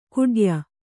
♪ kuḍya